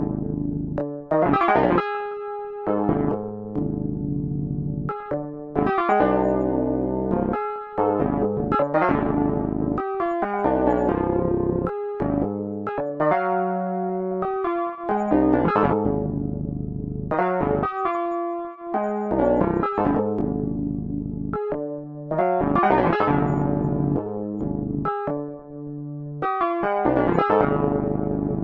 循环 " 疯狂的模拟合成器序列 - 声音 - 淘声网 - 免费音效素材资源|视频游戏配乐下载
模拟合成器16步序列随机运行。